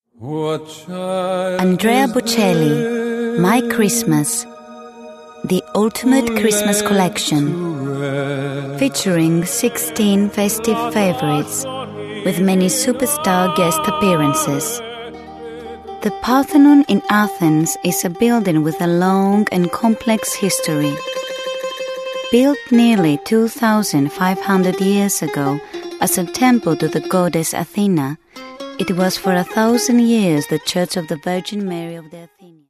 Greek, Female, 20s-40s